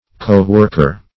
co-worker \co`-work"er\, coworker \co`work"er\(k?`w?rk"?r), n.